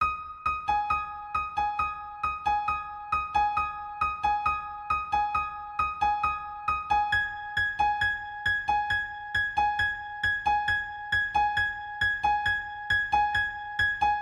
Juice WRLD Type Piano
描述：BPM: 135 Key: D4 (I used Slow Trippet, so the bass has to be seven notes upper than D4, it means A4)
标签： 135 bpm Soul Loops Piano Loops 2.39 MB wav Key : D
声道立体声